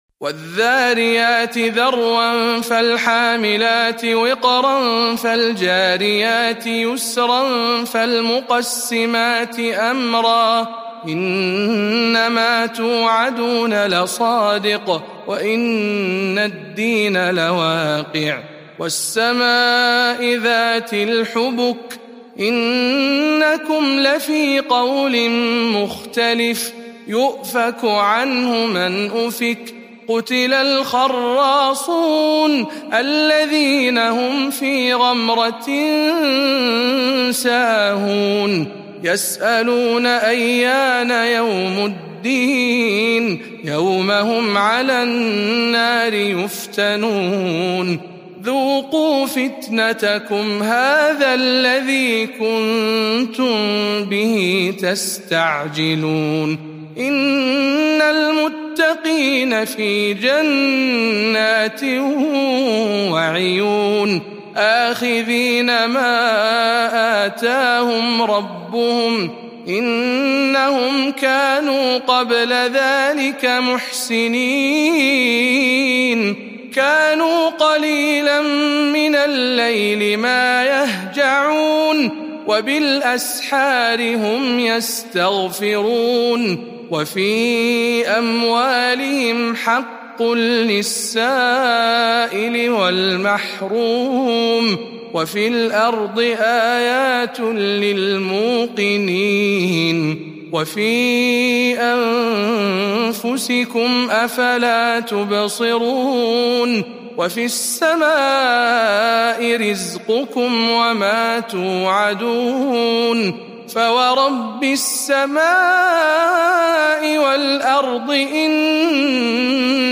سورة الذاريات برواية شعبة عن عاصم